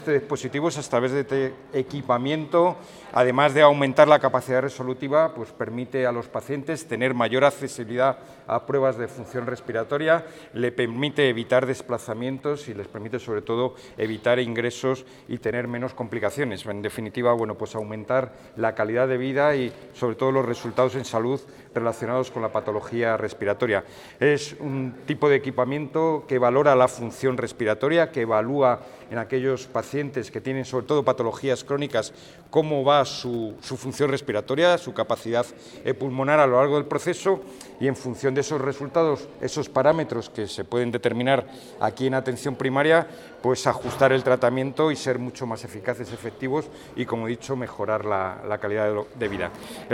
Consejería de Sanidad Jueves, 24 Abril 2025 - 1:30pm El director gerente del SESCAM, Alberto Jara, ha indicado hoy que la espirometría es una prueba diagnóstica fundamental en la evaluación de la función pulmonar, especialmente en el diagnóstico y manejo de enfermedades respiratorias crónicas. Su uso en Atención Primaria permite la detección precoz de enfermedades como el asma, la enfermedad pulmonar obstructiva crónica (EPOC) y otras patologías respiratorias. alberto_jara_funcionamiento_espirometro.mp3 Descargar: Descargar